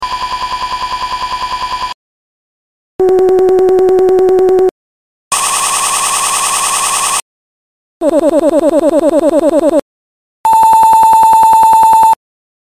Pixel sound of demonic Sprou#CapCut